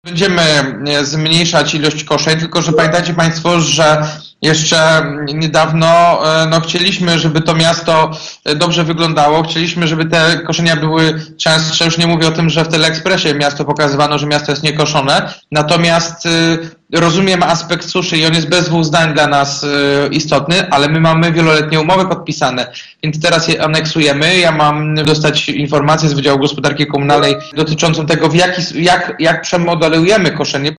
– Na razie koszenia wykonywane są zgodnie z obowiązującymi umowami – mówi prezydent Jacek Wójcicki: